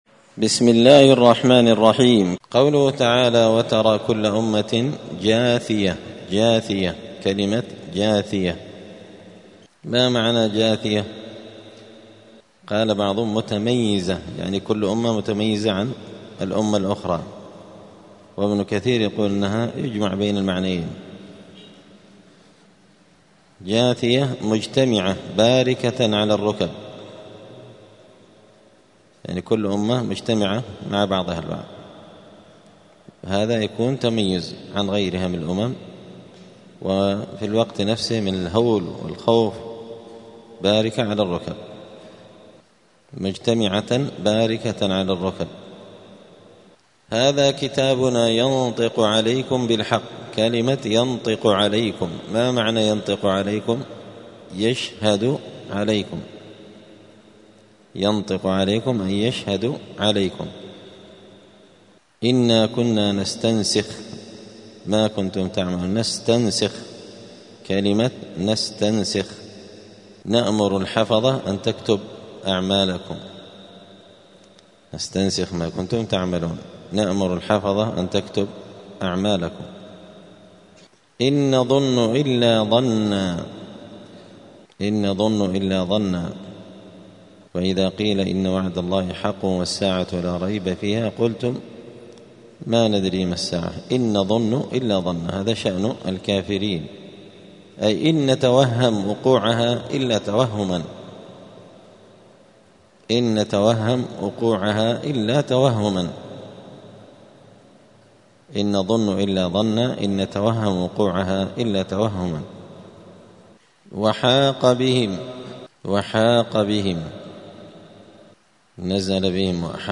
*(جزء فصلت سورة الجاثية الدرس 251)*
دار الحديث السلفية بمسجد الفرقان قشن المهرة اليمن